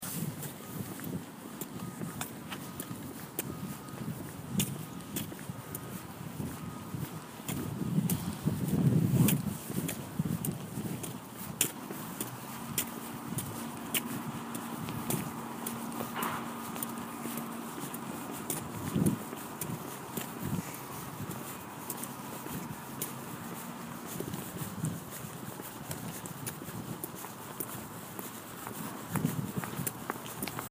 Field Recording #4
2/28/14, 2:20pm, Walking to class near the student center.
Sounds heard: foot steps, jacket material rubbing together, truck backing up noise, the wind, a construction vehicle, and someone walking by.